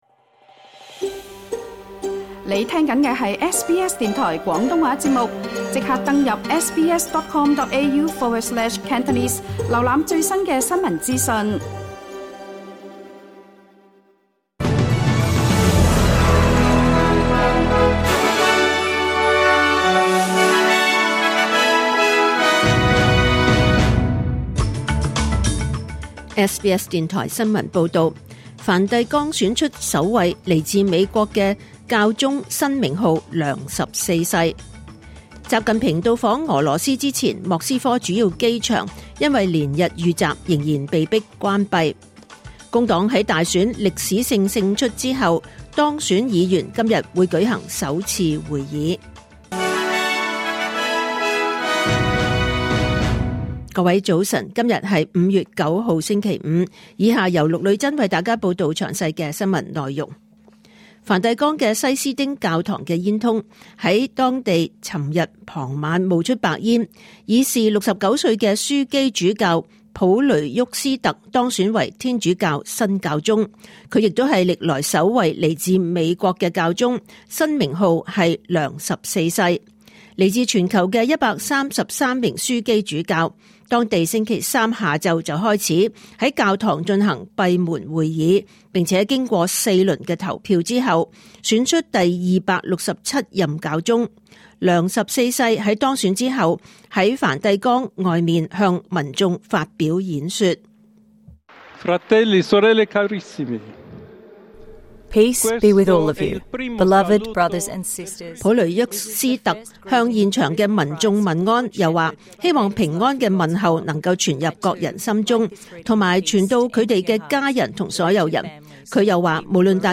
2025年5月9日SBS 廣東話節目九點半新聞報道。